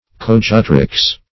Search Result for " coadjutrix" : The Collaborative International Dictionary of English v.0.48: Coadjutress \Co`ad*ju"tress\, Coadjutrix \Co`ad*ju"trix\, n. A female coadjutor or assistant.